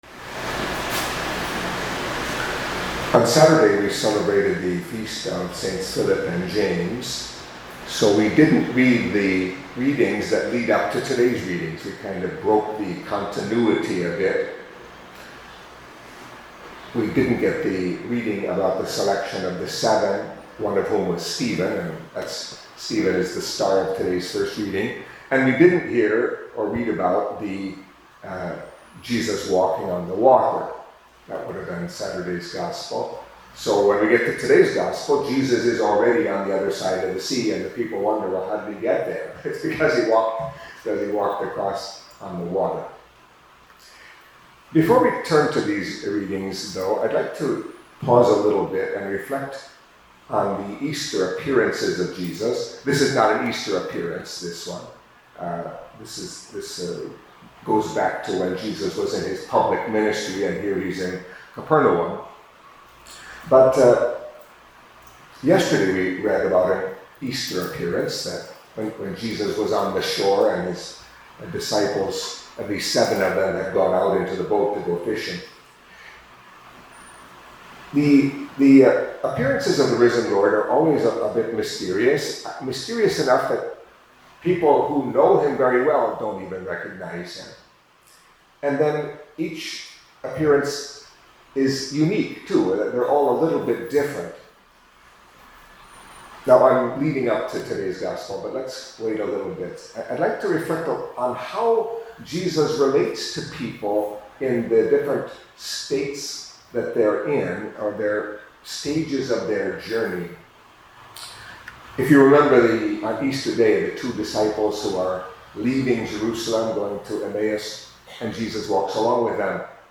Catholic Mass homily for Monday of the Third Week of Easter